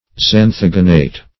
Xanthogenate \Xan"tho*gen*ate\, n.
xanthogenate.mp3